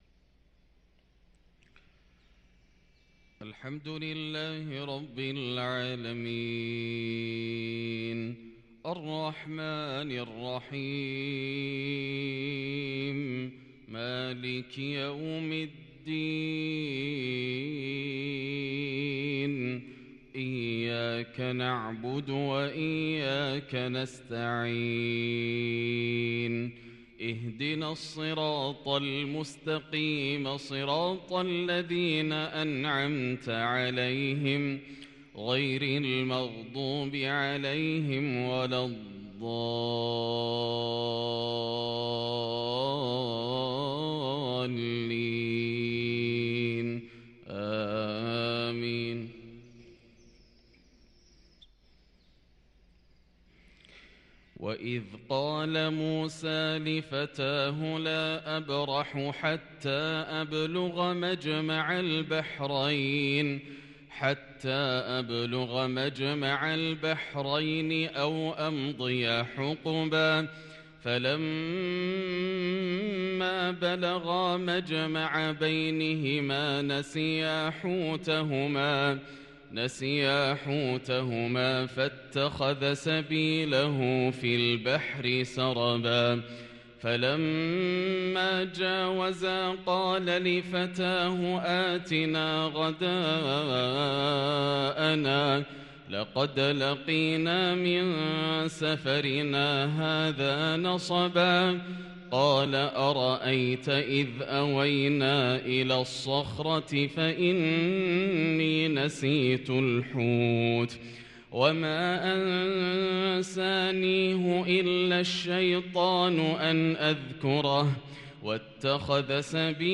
صلاة الفجر للقارئ ياسر الدوسري 4 جمادي الأول 1444 هـ
تِلَاوَات الْحَرَمَيْن .